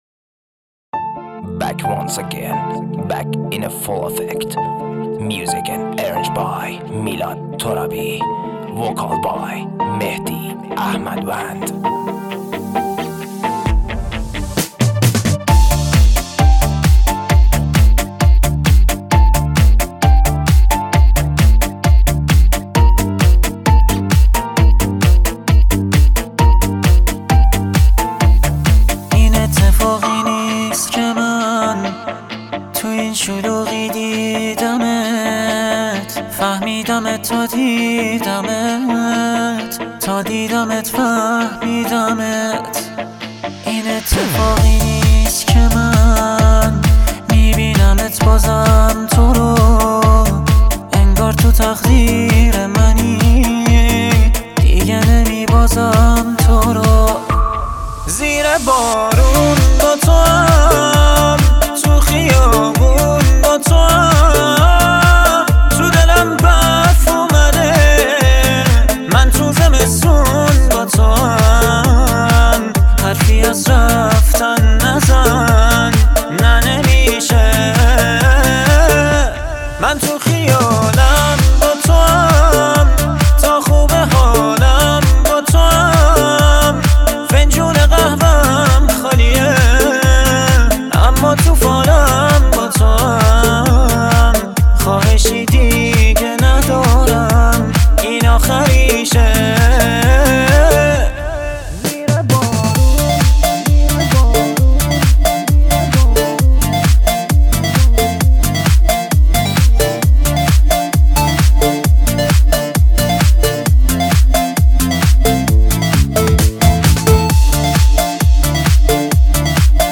اجرا شده در کنسرت تبریز
غمگین